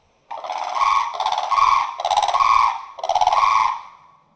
Unison Call | A duet performed by a pair, to strengthen their bond and protect their territory.
Blue-Crane-Unison.mp3